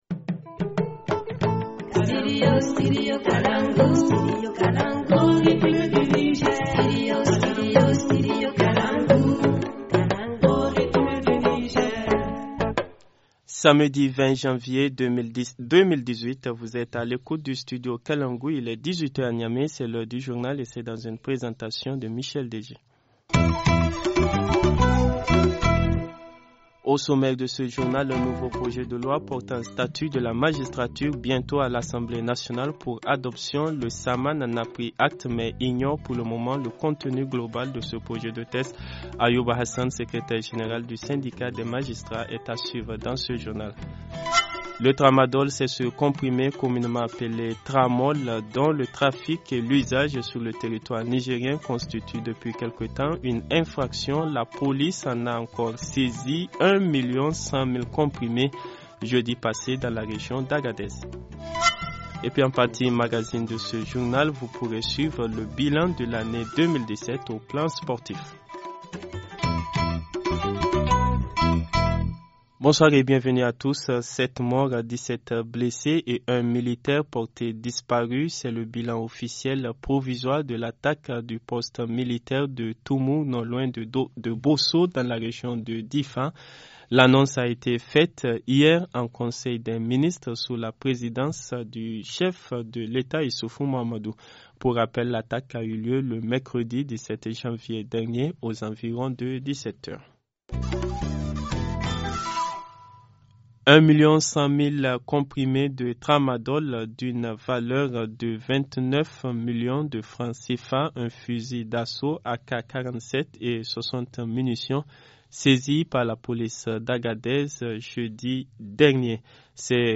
Journal du 20 Janvier 2018 - Studio Kalangou - Au rythme du Niger